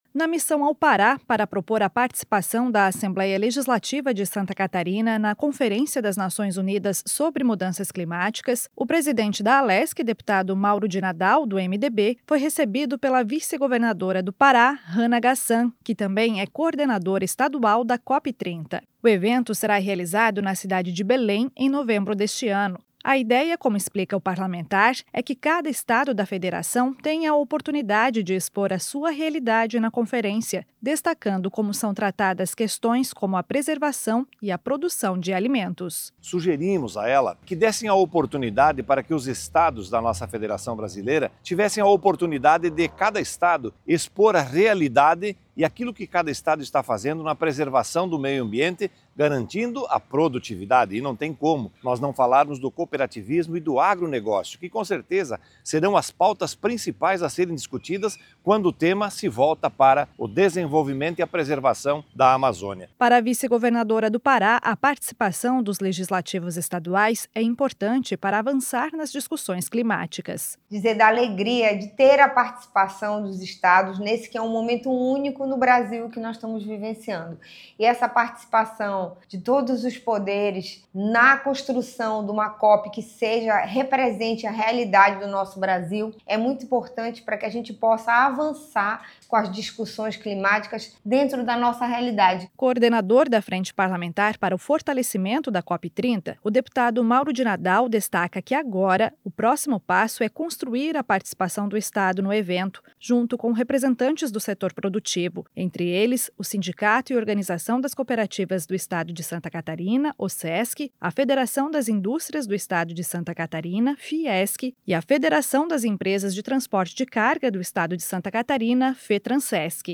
Entrevista com:
- deputado Mauro De Nadal (MDB), presidente da Assembleia Legislativa;
- Hana Ghassan (MDB), vice-governadora do Pará.